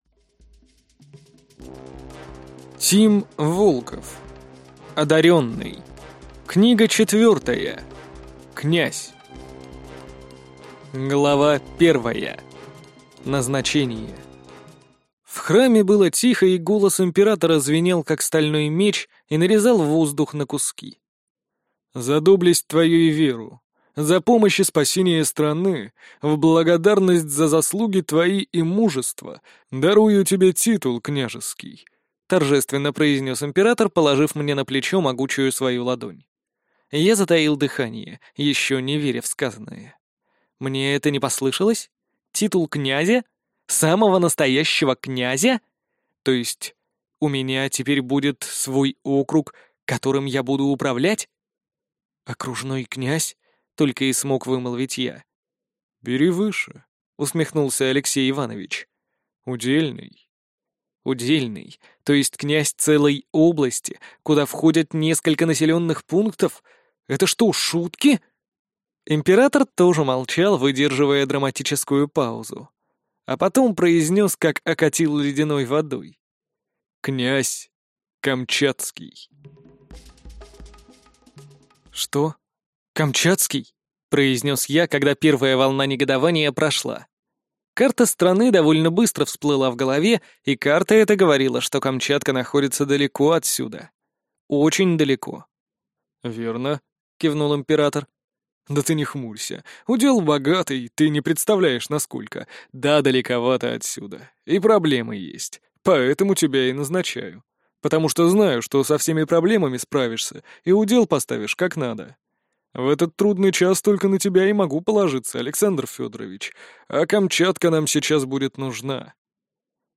Аудиокнига Одарённый. Книга 4. Князь | Библиотека аудиокниг